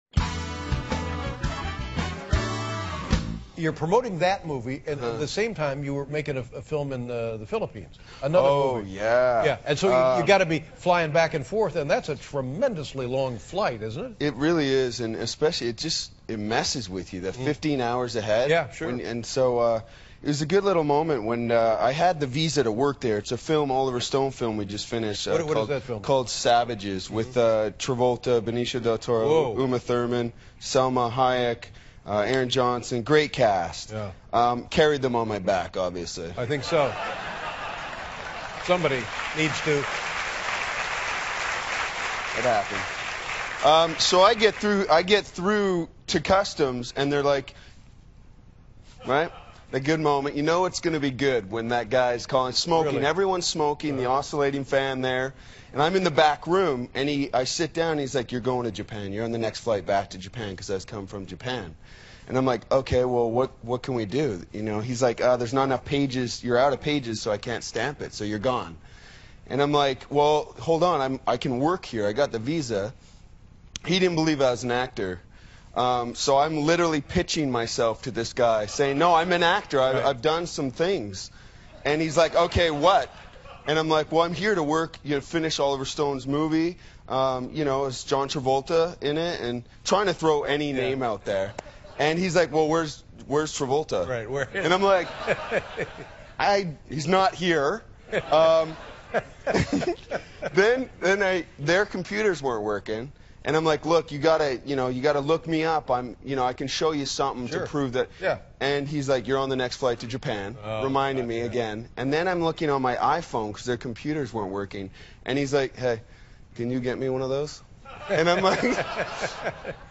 访谈录 2012-03-10&03-12 《金刚狼》牌王泰勒·克奇专访 听力文件下载—在线英语听力室